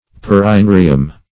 Perineurium \Per`i*neu"ri*um\, n. [NL., fr. Gr. peri` about +